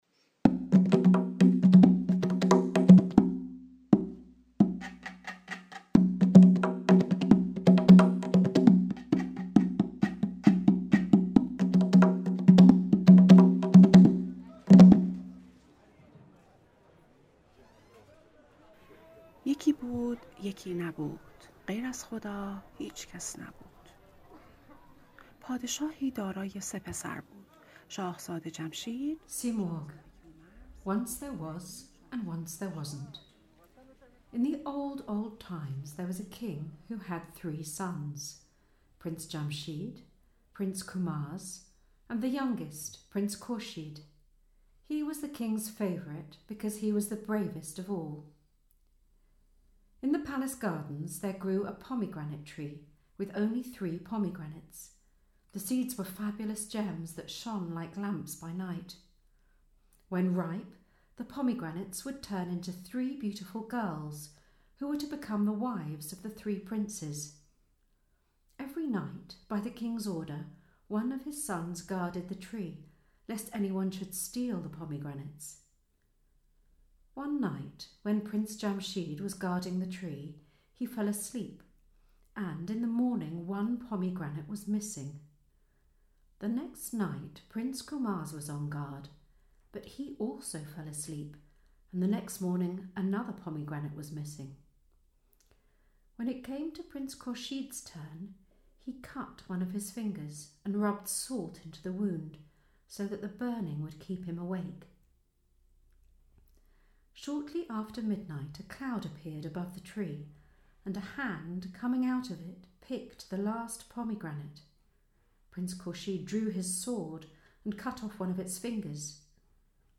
Es war einmal, es war einmal nicht ist eine Online-Ausstellung und Audio-Erfahrung, die als wachsende Sammlung von Volksmärchen konzipiert ist, die von Frauen mit Migrationshintergrund aus den Ländern entlang der alten Seidenstraße – die sich von Japan bis Italien erstreckt – erzählt und durch zeitgenössische Kunstfotografie illustriert werden.